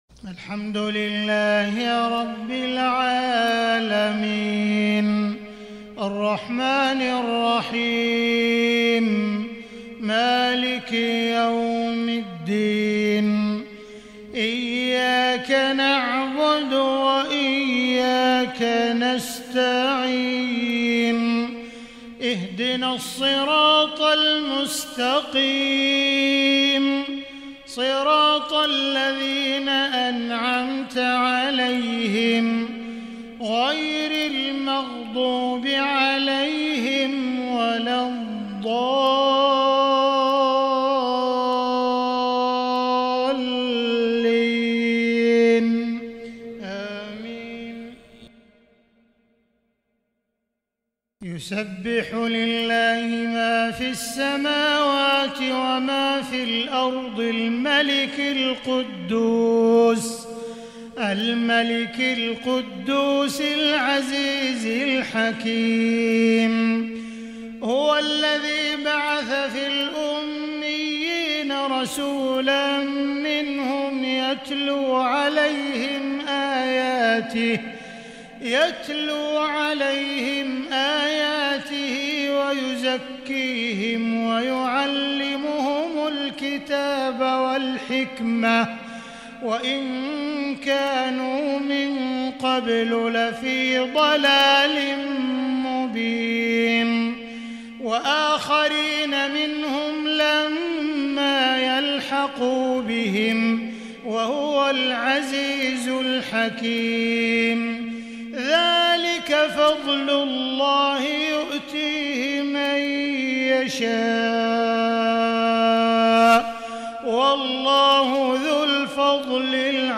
سورة الجمعة 🕊 بترتيل ترنمي رستي لمعالي الشيخ عبدالرحمن السديس من عشاء 23 صفر 1443هـ